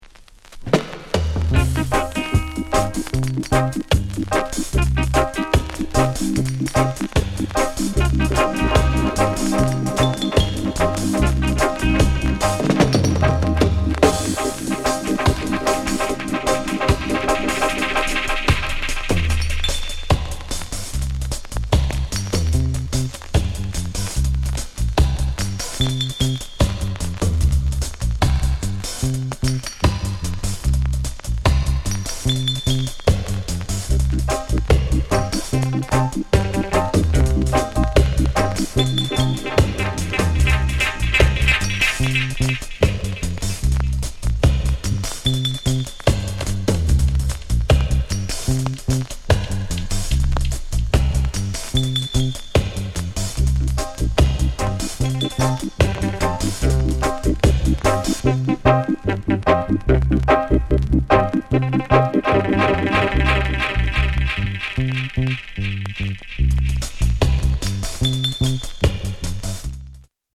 NICE INST DRUM SONG